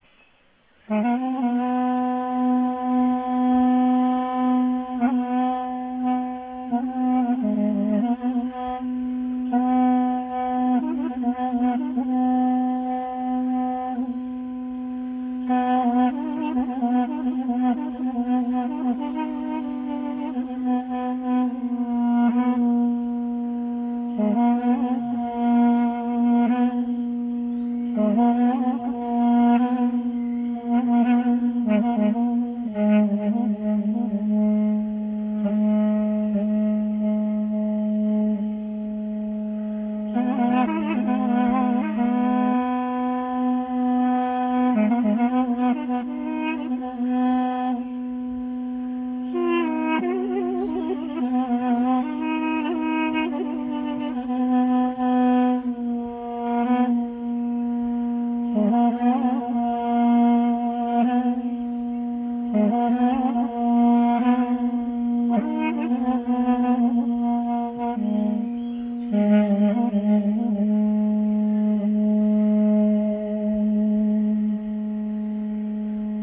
Le doudouk
Le doudouk est un hautbois à tuyau cylindrique fait en bois d'abricotier ou de mûrier et percé de huit trous.
Le son du doudouk est grave et chaud et rappelle un peu celui de la clarinette dans le registre bas ou du cor anglais. L'étendue du doudouk est assez limitée puisqu'elle ne dépasse pas une octave et une tierce.
doudouk.rm